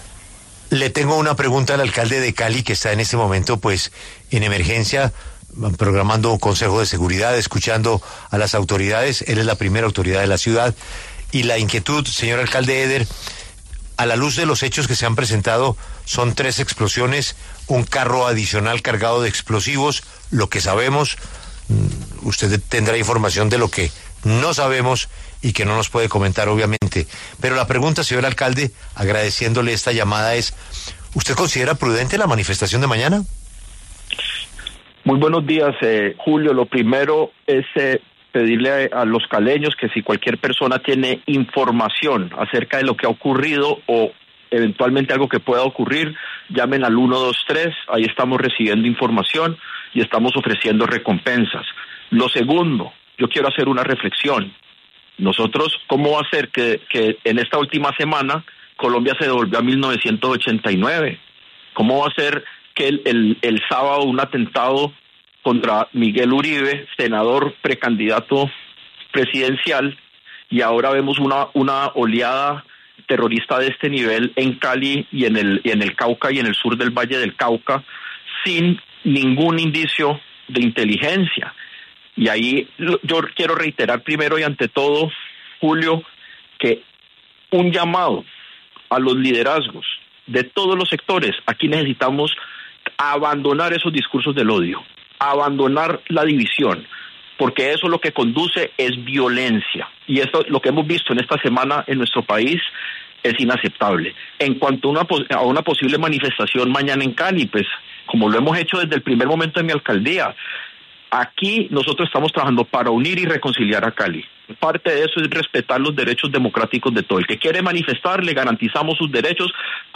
Ante los recientes ataques en zonas del Cauca, el alcalde de Cali, Alejandro Éder, conversó ante los micrófonos de La W con Julio Sánchez Cristo y expresó sus preocupaciones ante la situación actual en el país.